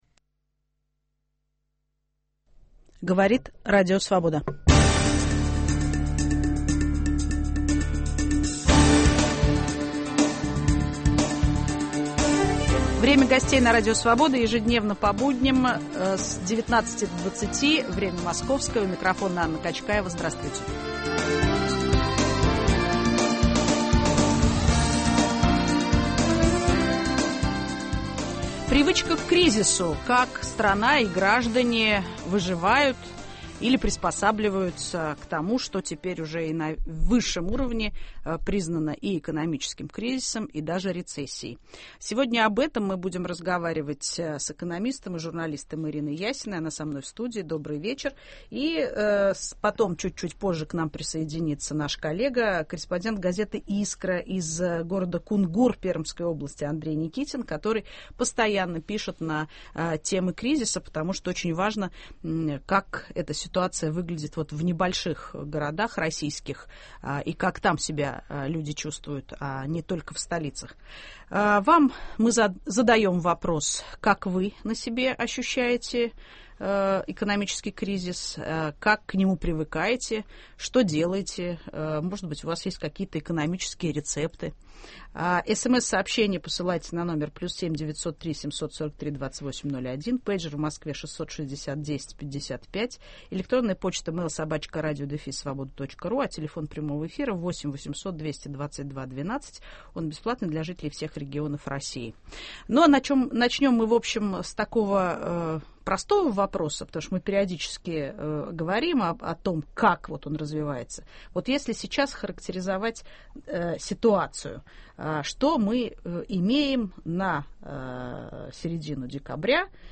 Привычка к кризису. Технология жизни во времена экономического спада. В студии